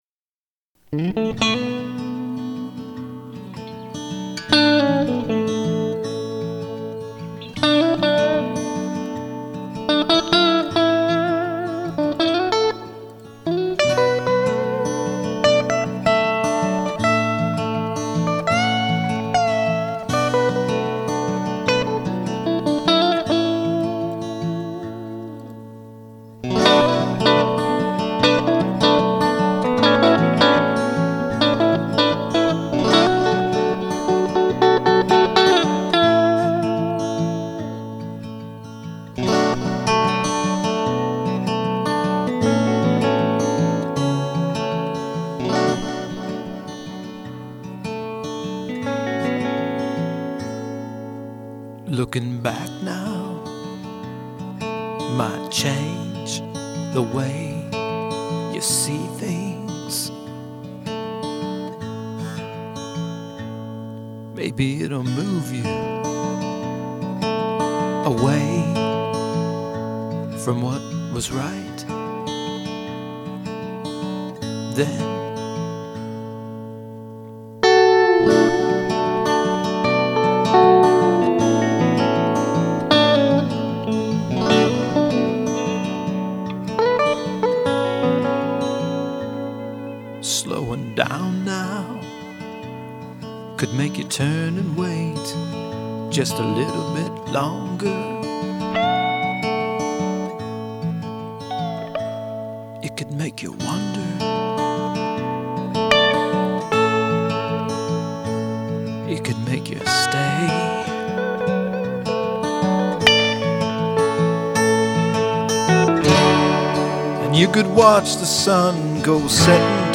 Original folk, traditional gospel, Russian and Chilean folk.